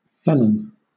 Ääntäminen
Southern England
IPA : /ˈfɛlən/